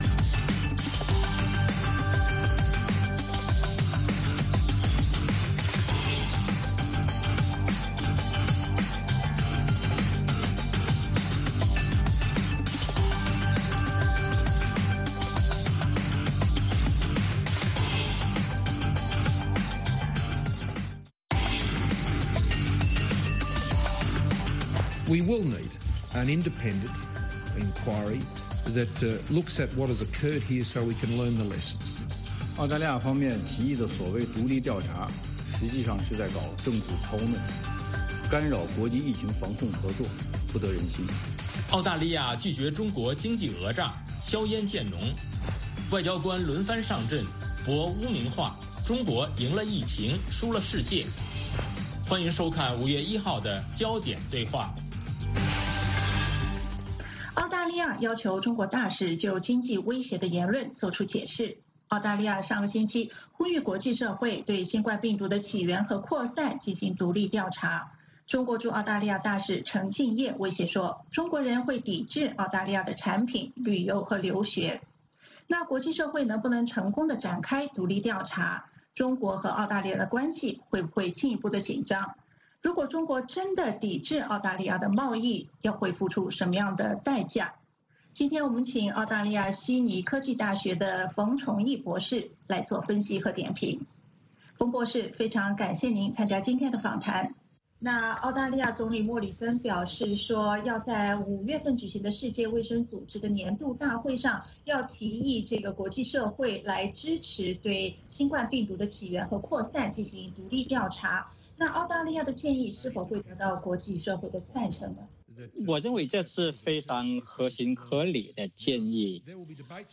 《焦点对话》追踪国际大事、聚焦时事热点。多位嘉宾分析、解读和评论新闻。